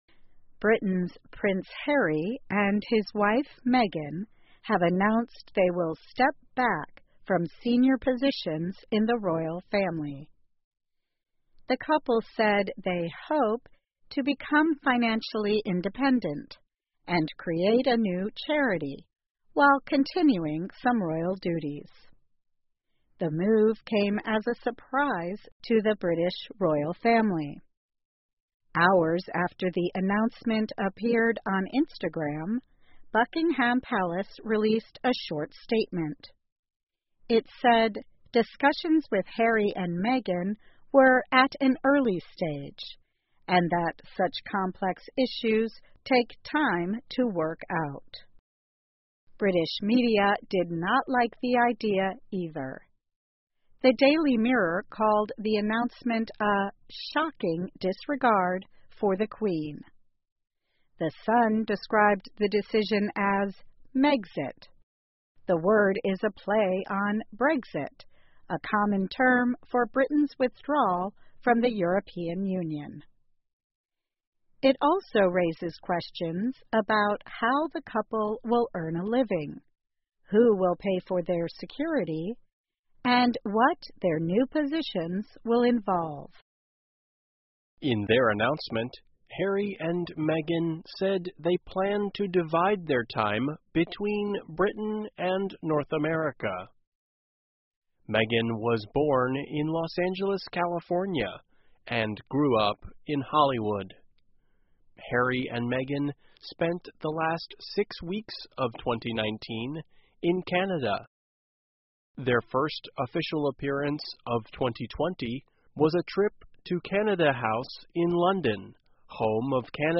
VOA慢速英语2019 哈里梅根宣布退出英国王室 听力文件下载—在线英语听力室